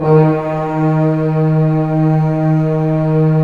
Index of /90_sSampleCDs/Roland - Brass, Strings, Hits and Combos/ORC_Orc.Unison p/ORC_Orc.Unison p